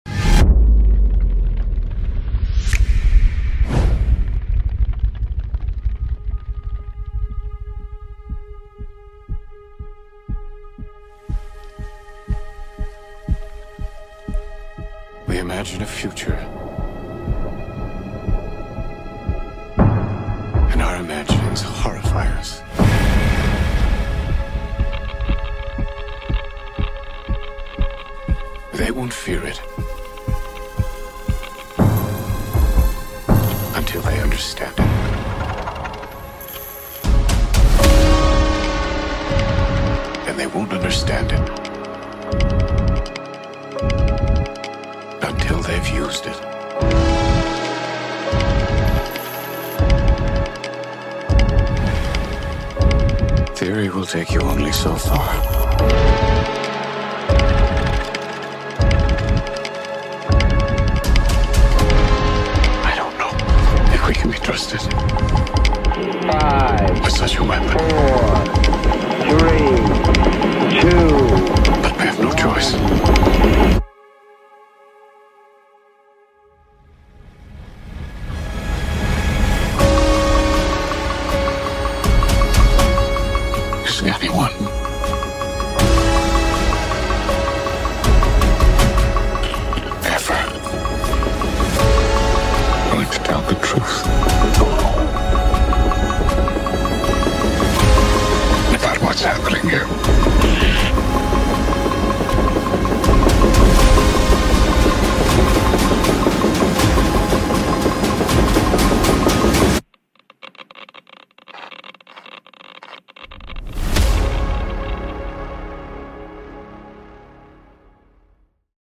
What if the OPPENHEIMER Trailer.. had my SOUND 💣 Music & Sound Design by Me💥